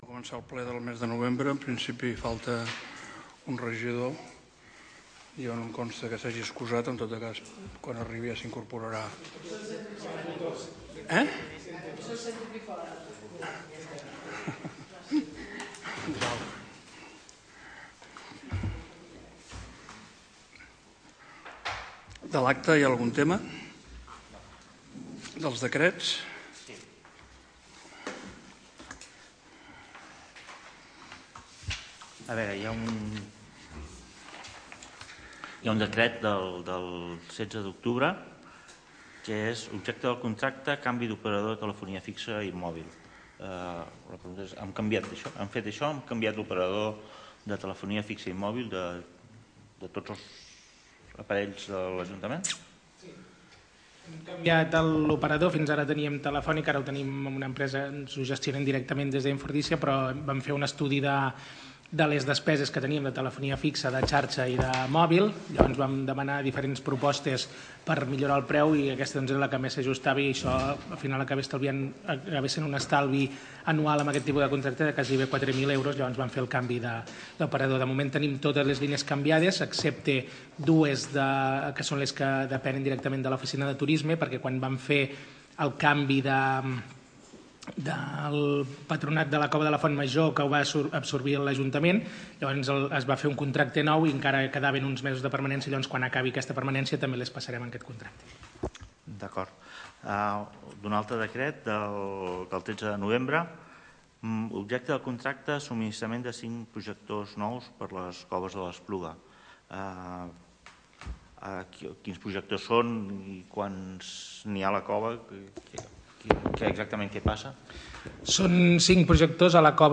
Sessió ordinària del Ple Municipal de l’Espluga corresponent al mes de novembre celebrada a la Sala de Plens de l’Antic Hospital el dijous 29 de novembre amb el següent ordre del dia: